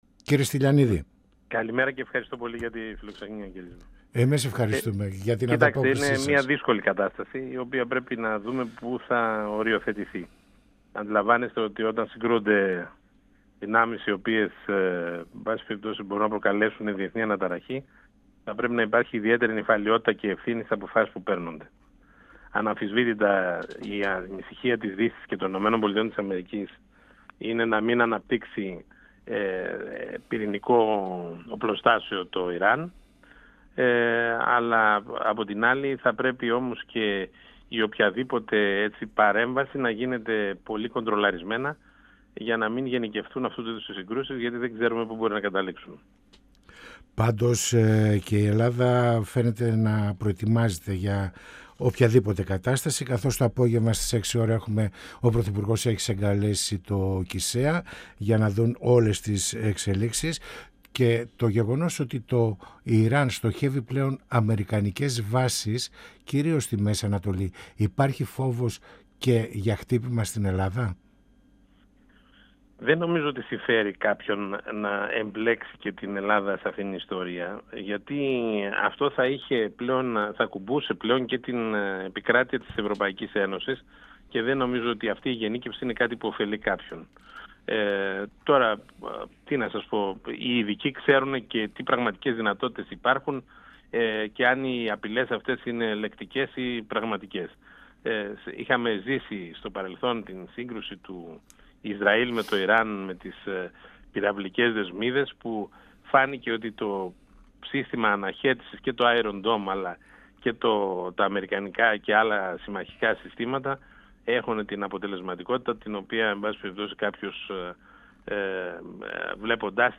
Στις πρώτες ώρες του πολέμου στη Μέση Ανατολή, στους στόχους ΗΠΑ και Ισραήλ σε σχέση με το θεοκρατικό καθεστώς στο Ιράν, τις πρώτες απώλειες ανθρώπινων ζωών αλλά και την πολλαπλή αντίδραση του Ιράν με μη αναμενόμενες επιθέσεις σε γειτονικές αραβικές χώρες του Κόλπου αναφέρθηκε ο Βουλευτής Ροδόπης της ΝΔ και Επ. Καθηγητής Νομικής στο Ευρωπαϊκό Πανεπιστήμιο Κύπρου Ευριπίδης Στυλιανίδης , μιλώντας στην εκπομπή «Πανόραμα Επικαιρότητας» του 102FM της ΕΡΤ3.